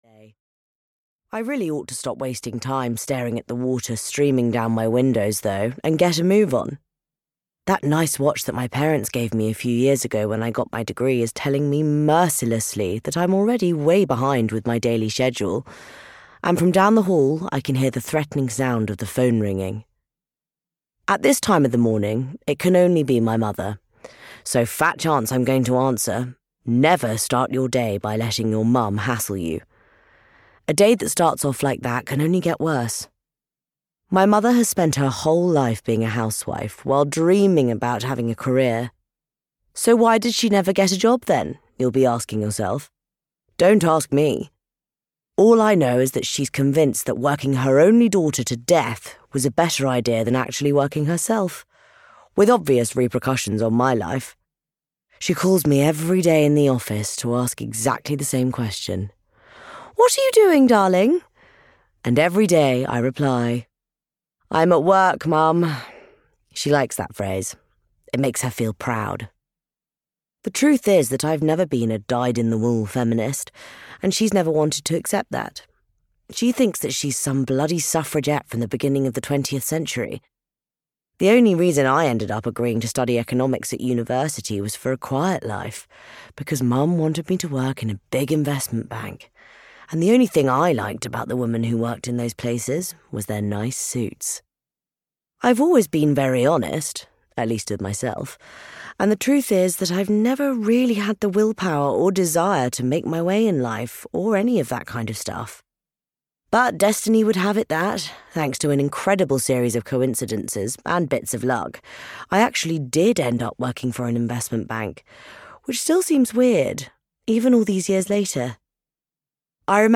You Drive Me Crazy (EN) audiokniha
Ukázka z knihy